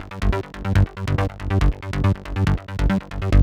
VTS1 Space Of Time Kit Bassline